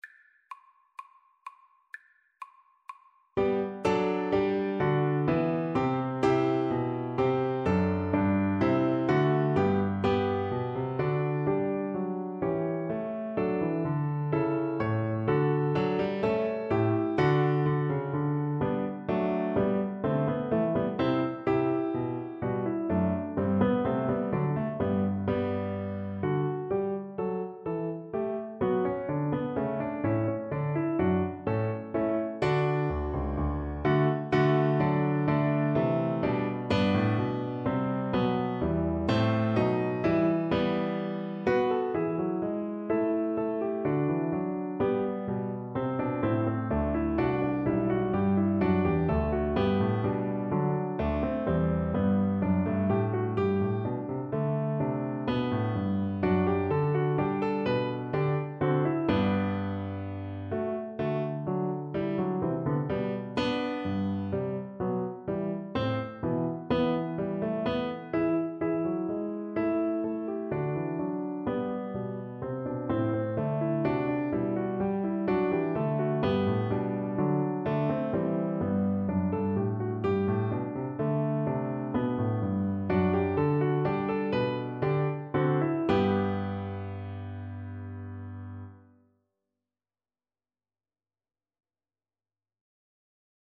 Free Sheet music for Violin
Play (or use space bar on your keyboard) Pause Music Playalong - Piano Accompaniment Playalong Band Accompaniment not yet available transpose reset tempo print settings full screen
Violin
Allegretto = 126
G major (Sounding Pitch) (View more G major Music for Violin )
Classical (View more Classical Violin Music)
handel_bourree_VLN_kar1.mp3